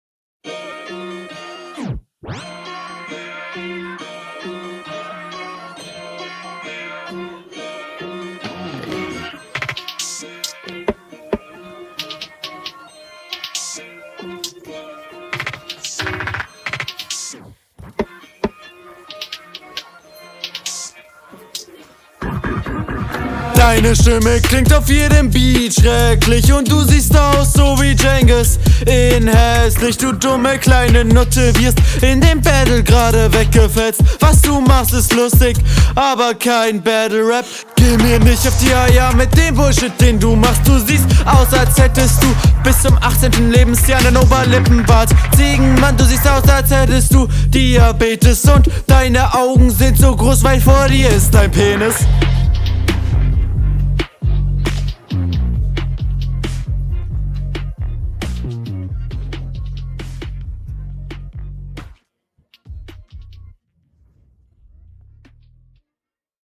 Boah und ich dachte meine Battlerapbeats wären Scheiße aber n Phaser aufn Klavier zu packen …